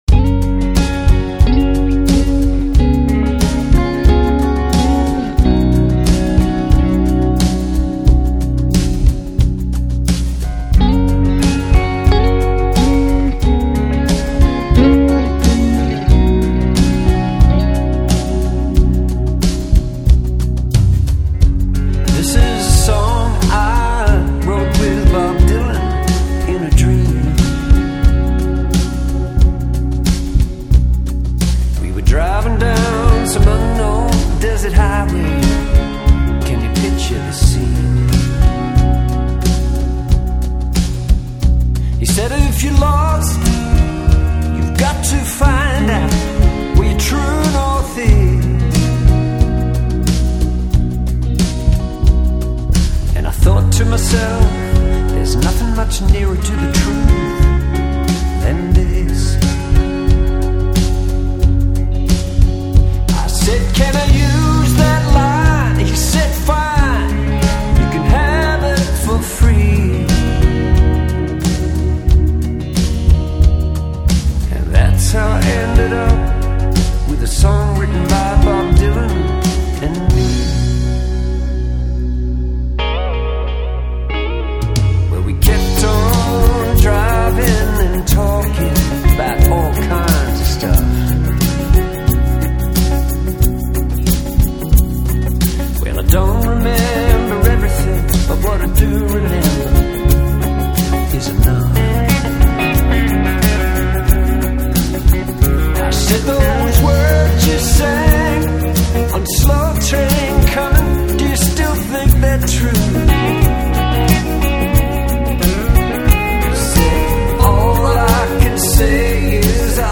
Australian singer/songwriter and guitarist.